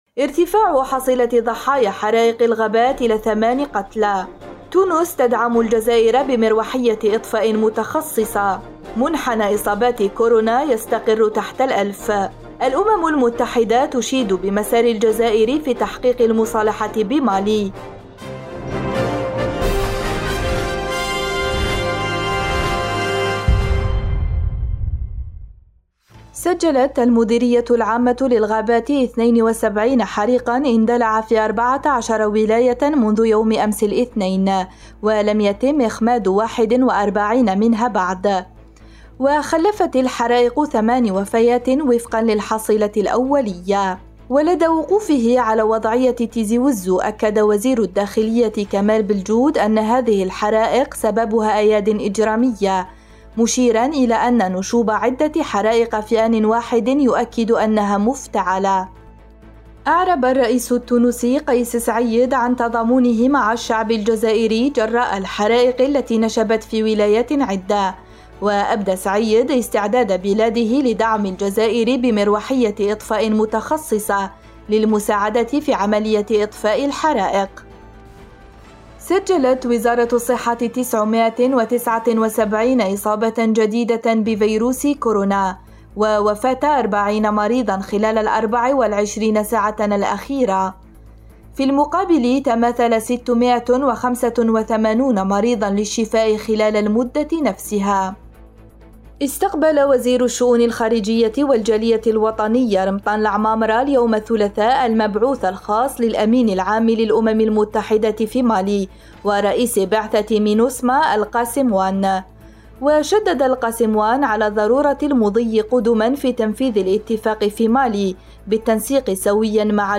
النشرة الرقميةفي دقيقتين